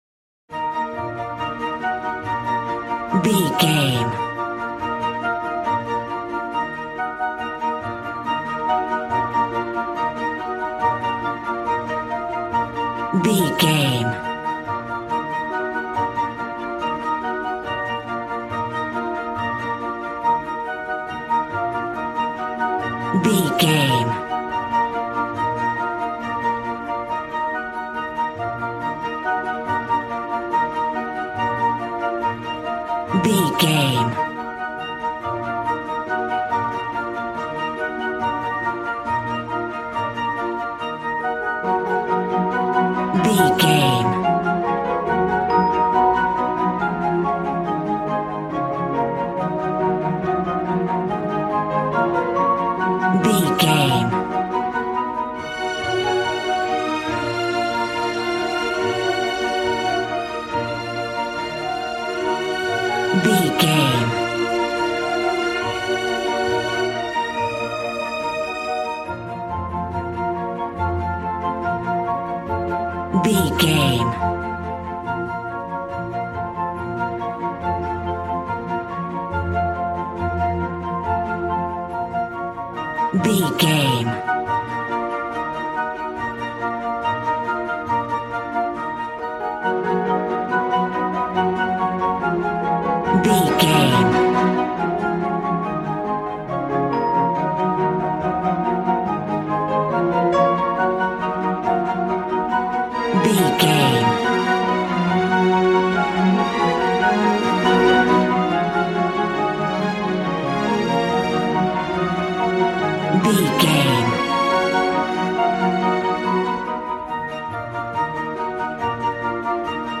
Regal and romantic, a classy piece of classical music.
Ionian/Major
B♭
regal
strings
violin